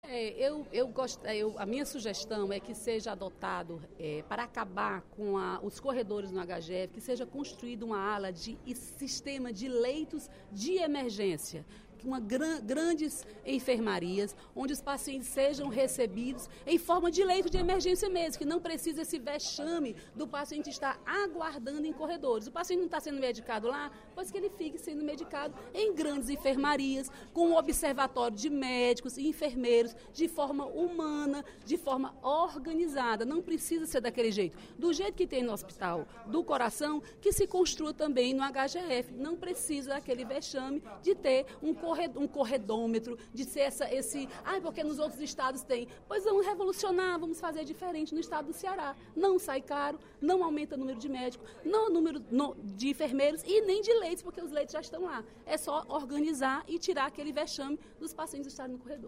A deputada Dra. Silvana (PMDB) fez pronunciamento, durante o primeiro expediente da sessão plenária desta terça-feira (30/05), para elogiar a iniciativa do Governo em inaugurar novos leitos no Hospital de Messejana.